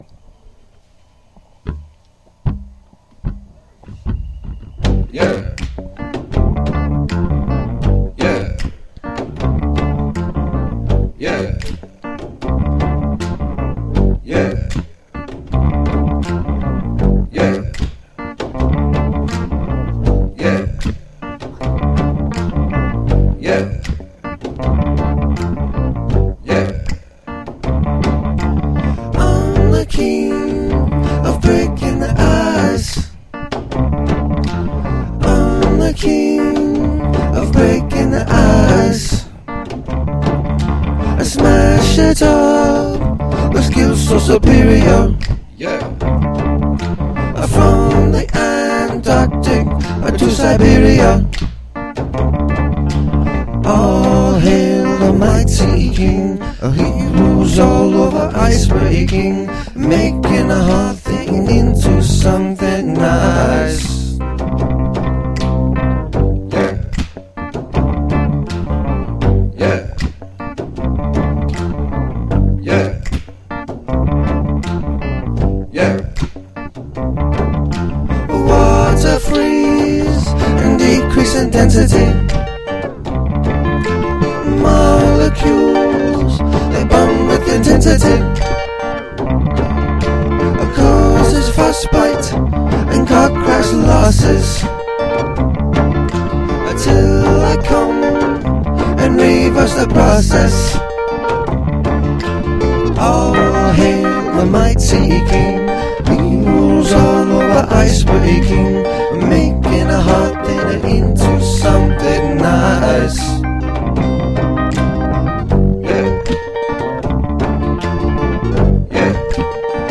Significant Use of Shouting